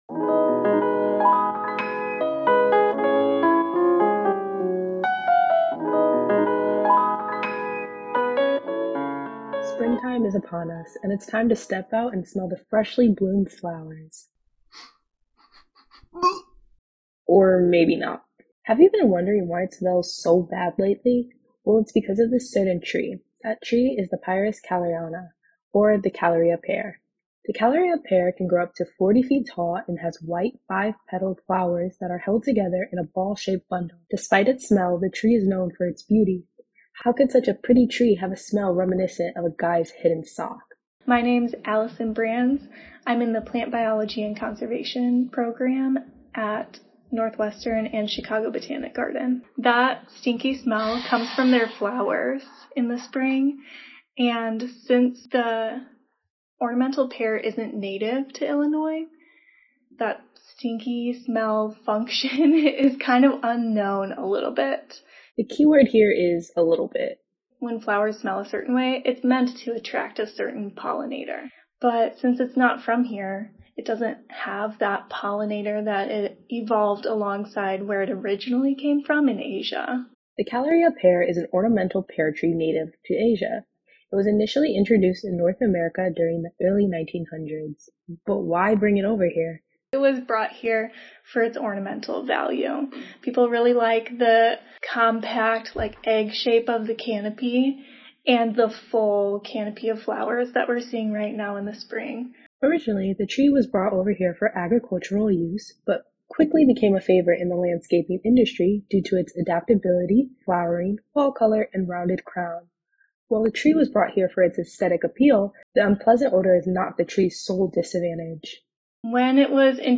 Free Background Music for Videos